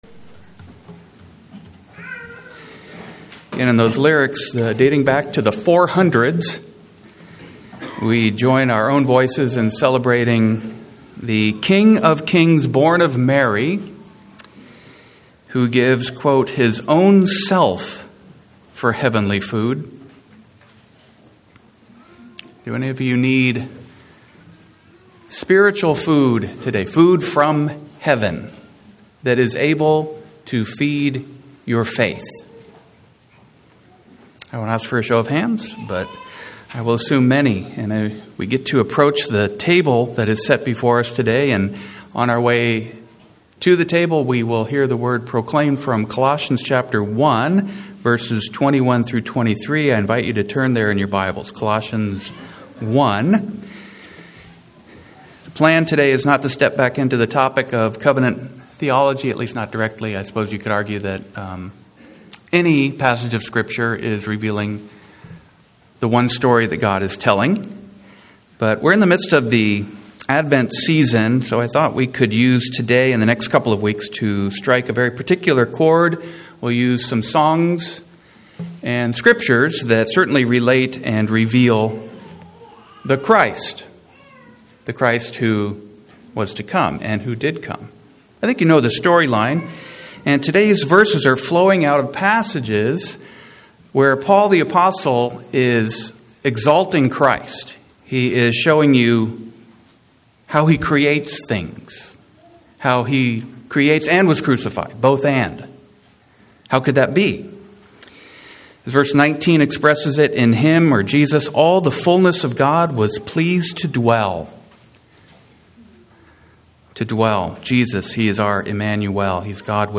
Occasional Sermons